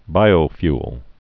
(bīō-fyəl)